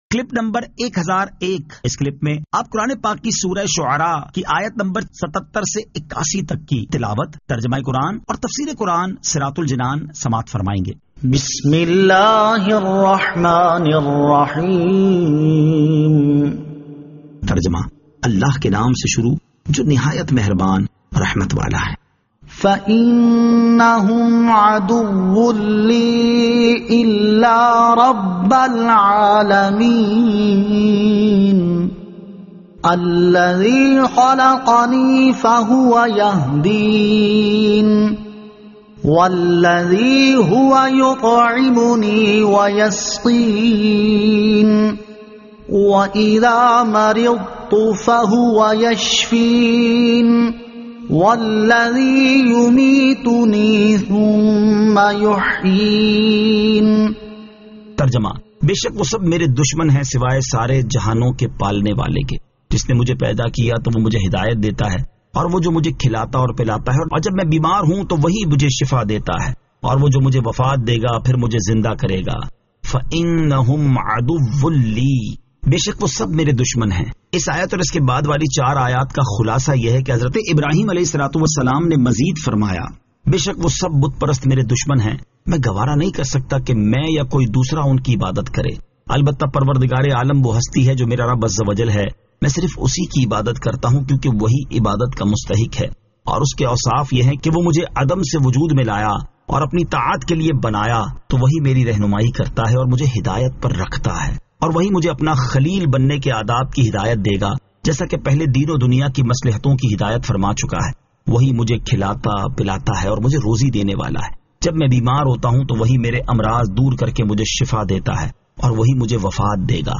Surah Ash-Shu'ara 77 To 81 Tilawat , Tarjama , Tafseer